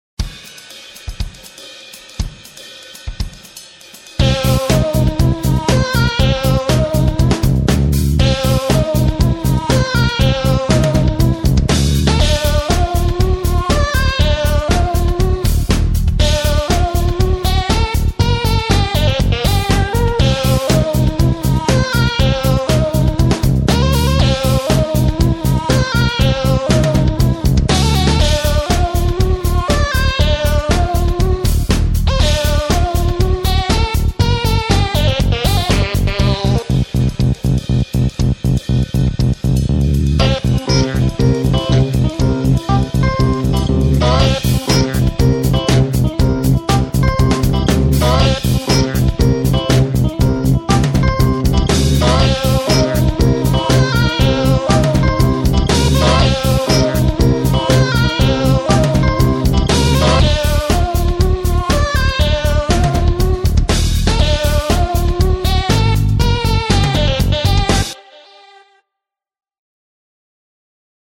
描述：舞蹈和电子音乐|忧郁
Tag: 合成器 贝司